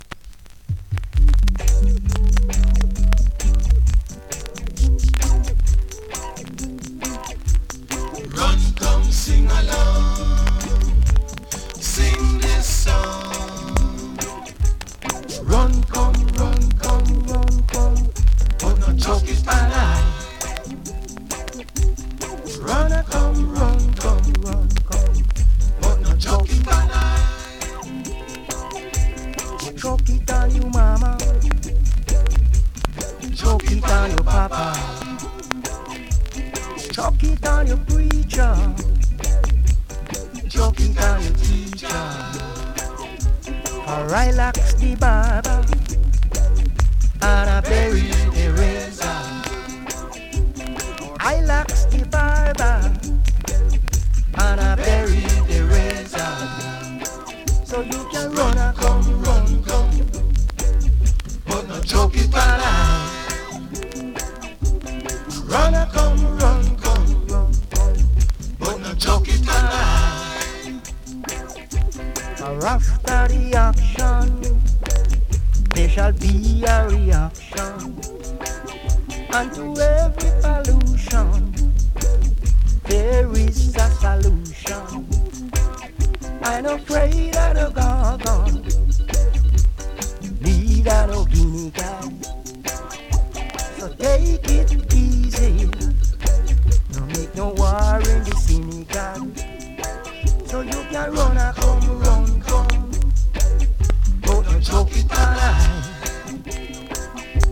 SKA〜REGGAE
スリキズ、ノイズそこそこありますが